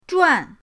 zhuan4.mp3